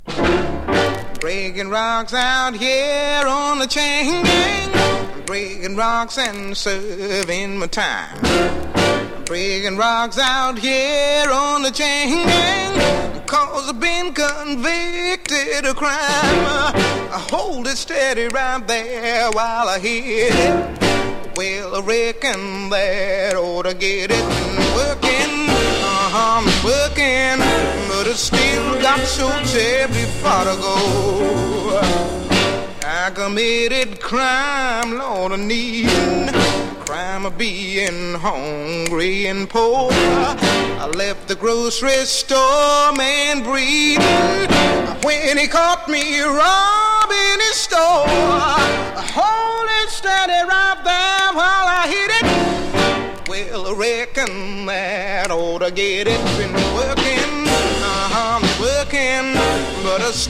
ヒップな歌声堪能。